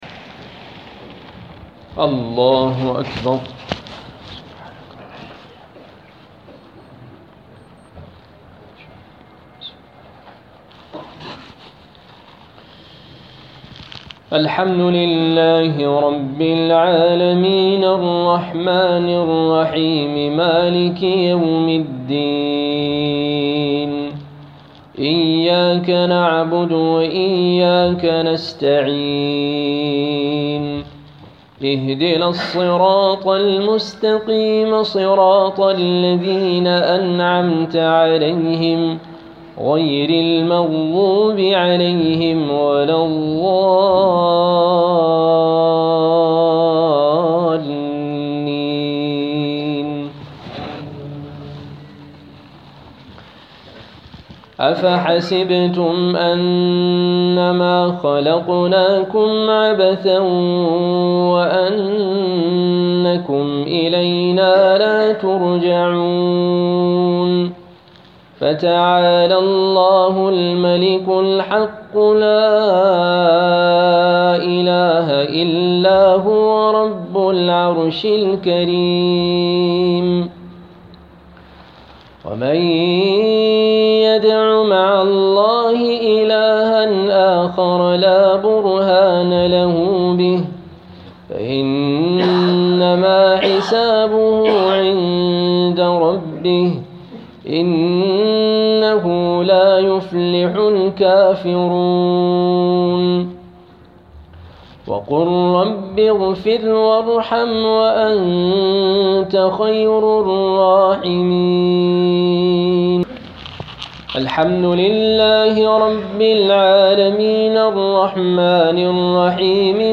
Qiraat Recitation